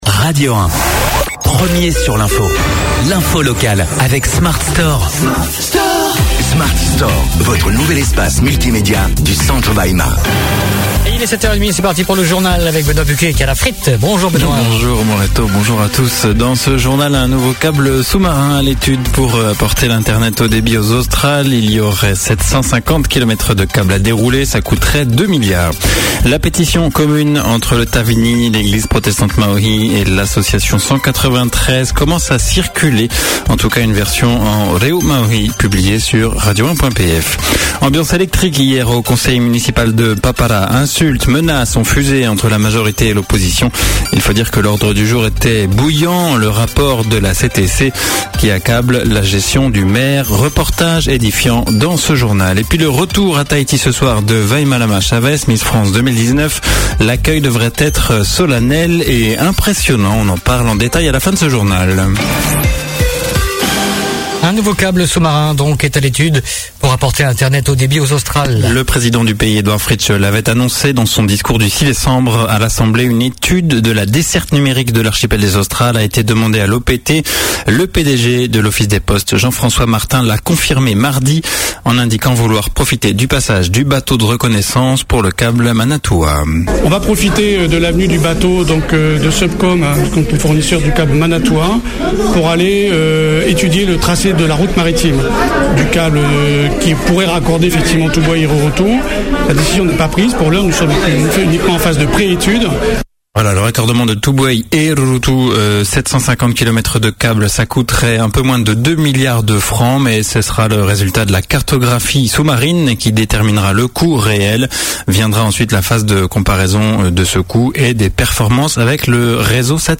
Le journal de 7h30, le 20/12/2018